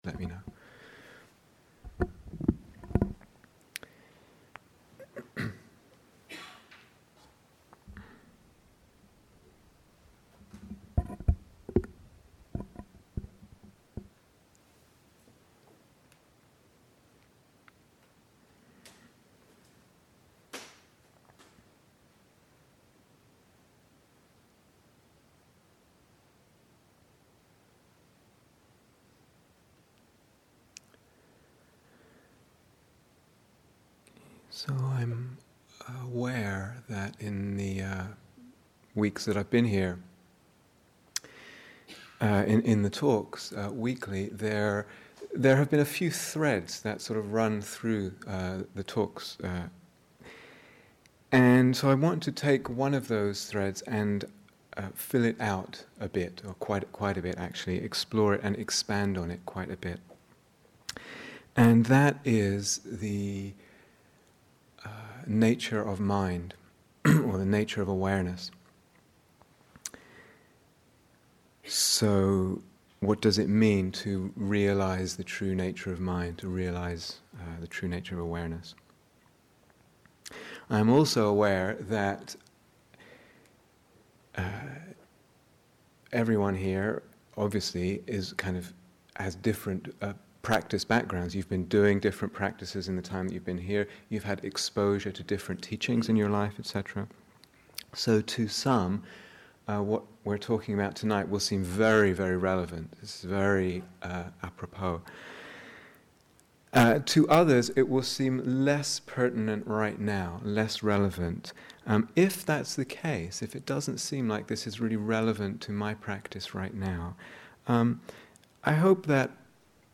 But eventually we must see even beyond this to know the ultimate nature of the mind - empty, completely groundless, and dependently-arisen - a seeing which brings an even deeper freedom. This talk explores some of the ways this realization might be encouraged and developed in meditation.